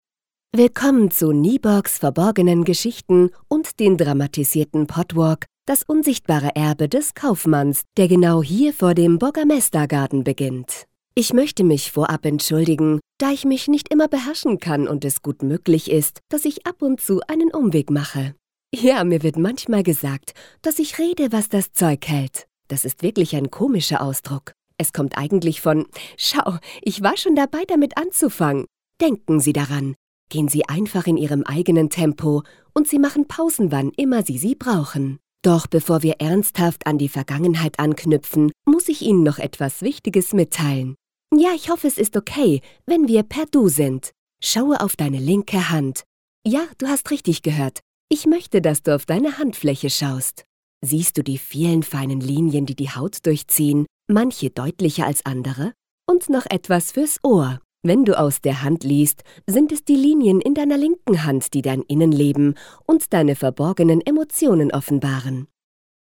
Warme, freundliche mittlere Stimmlage.
Sprechprobe: Industrie (Muttersprache):
Warm voice, middle aged